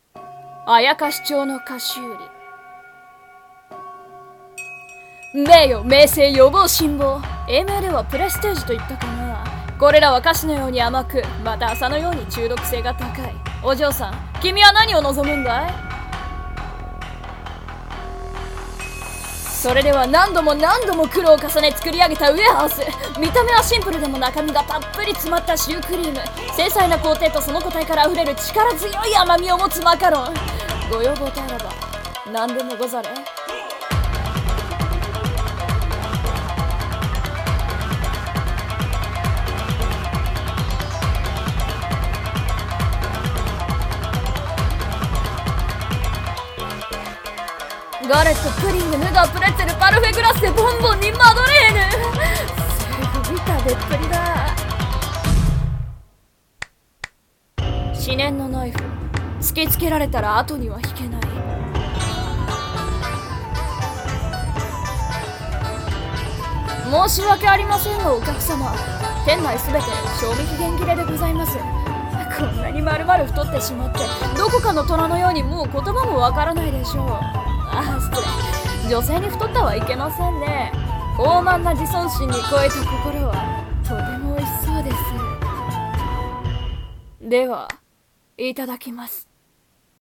CM風声劇「妖町の菓子売」